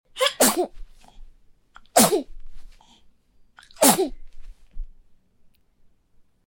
دانلود آهنگ زنگ اس ام اس عطسه بچه از افکت صوتی انسان و موجودات زنده
جلوه های صوتی